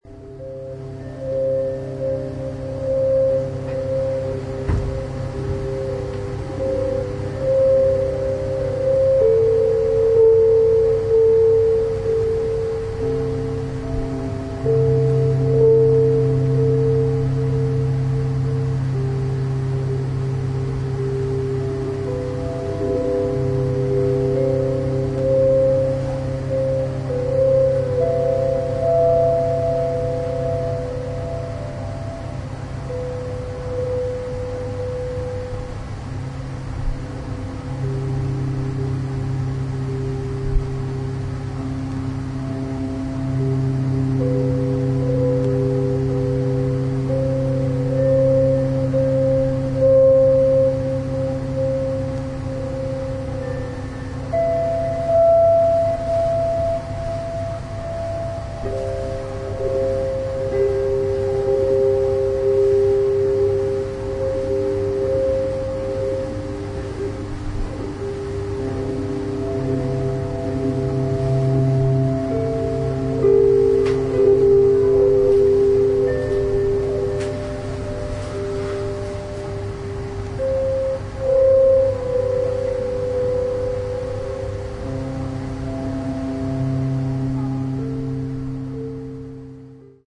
沈黙の中に響く微かなノイズ。
ギターの透明な響きは美しい終わりの光景へと溶けていきます。